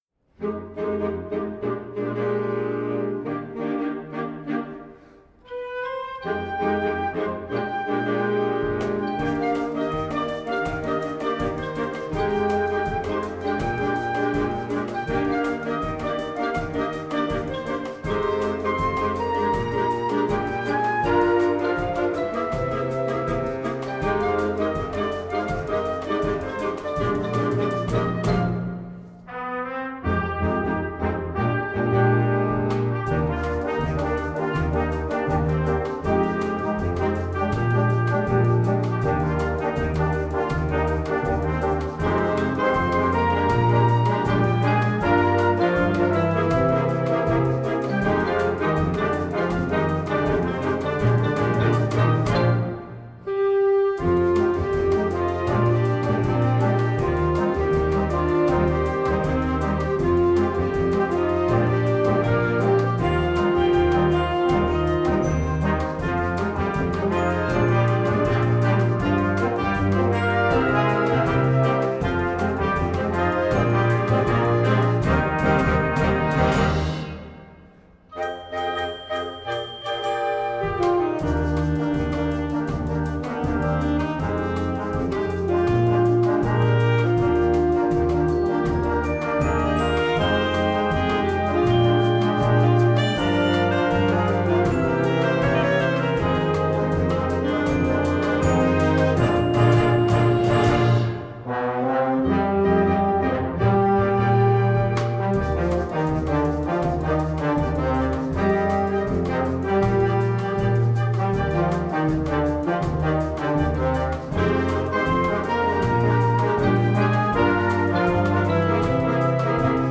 Gattung: Moderner Einzeltitel für Jugendblasorchester
Besetzung: Blasorchester
eingängige Melodien, Bossa-Nova-Rhythmen und Jazz-Harmonien
verstärkt synkopische Rhythmen
Altsaxophon-Solisten